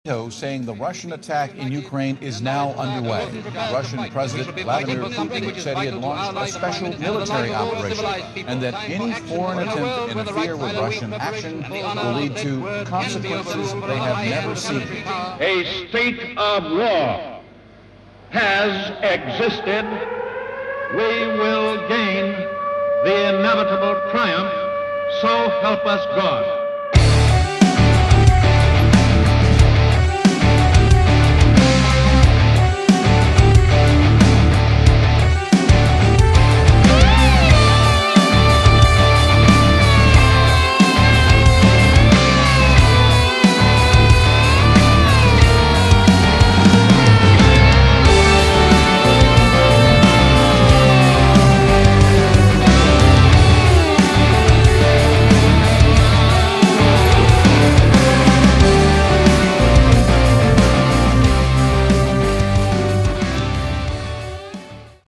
Category: AOR
guitars
keyboards
drums
backing vocals